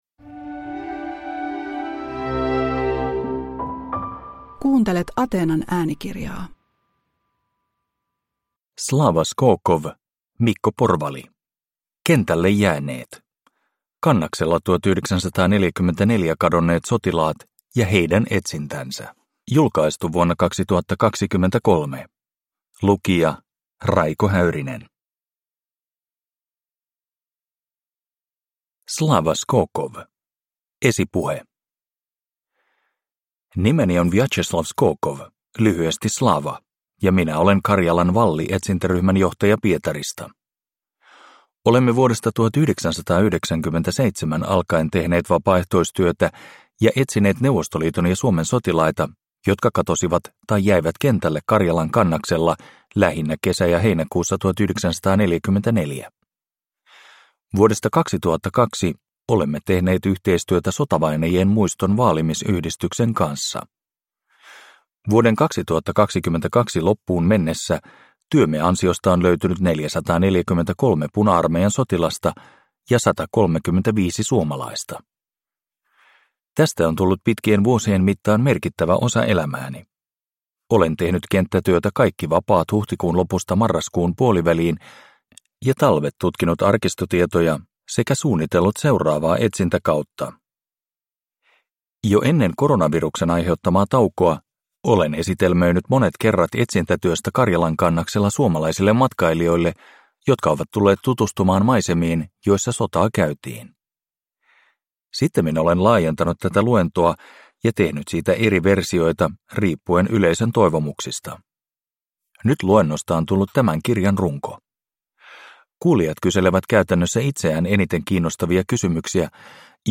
Kentälle jääneet – Ljudbok – Laddas ner